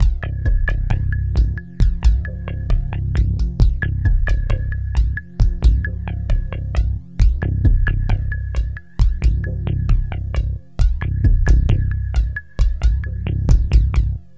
Downtempo 18.wav